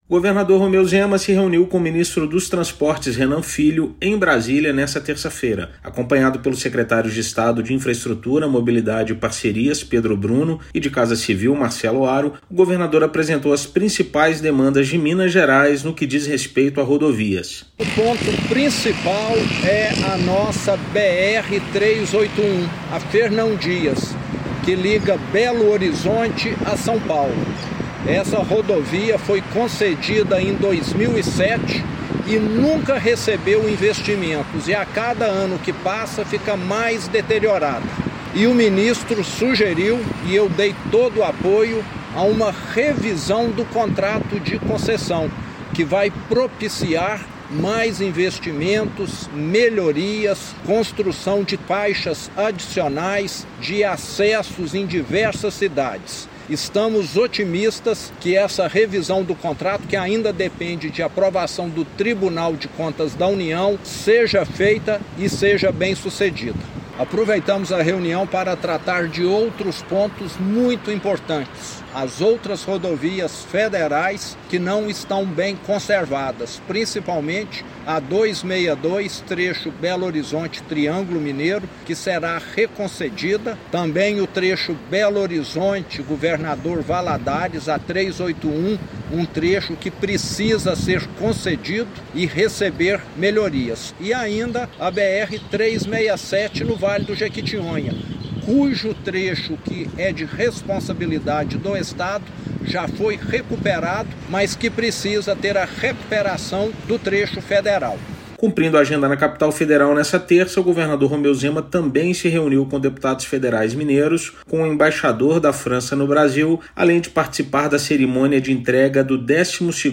[RÁDIO] Governador cumpre agendas de trabalho em Brasília reforçando o diálogo com setores políticos
Chefe do Executivo levou demandas de Minas para o ministro dos Transportes, como obras nas BRs 262 e 367, e necessidade de concessão e melhorias do trecho entre BH e Valadares da BR-381. Ouça a matéria de rádio